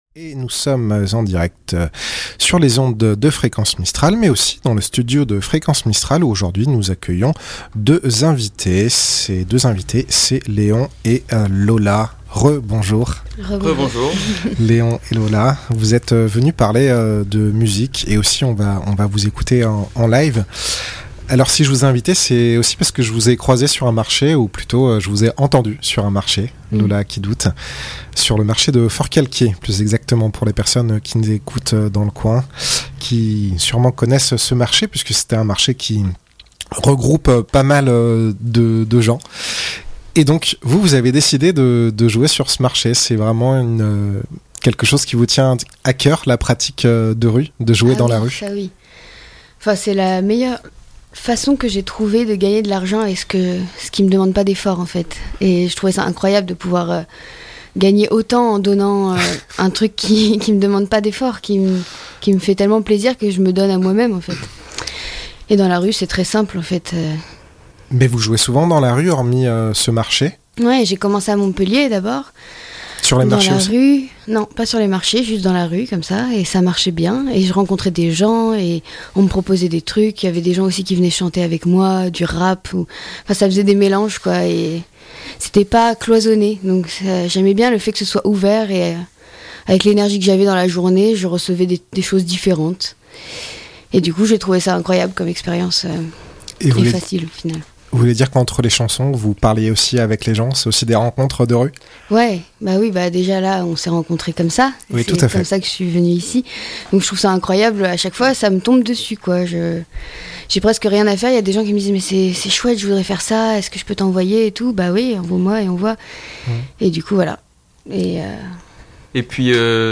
portrait d'artiste
c'est une voix et une guitare, avec un univers toujours intime et sensible.
Interview